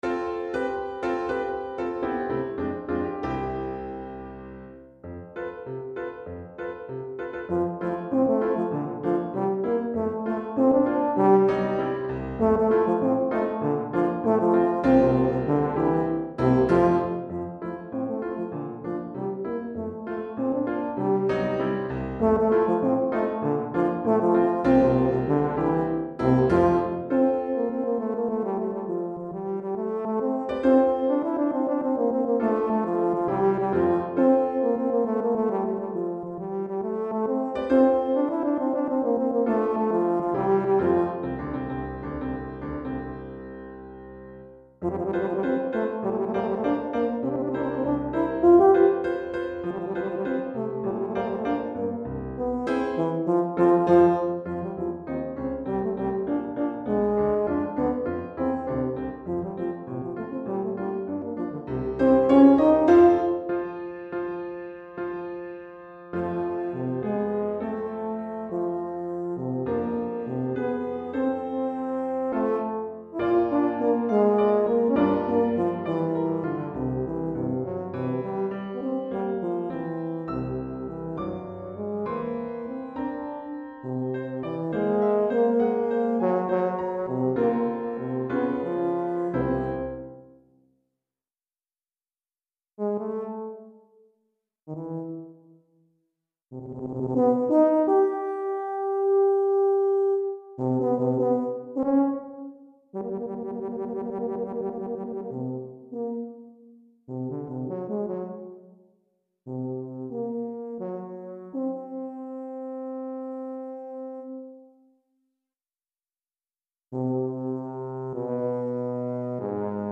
Répertoire pour Tuba, euphonium ou saxhorn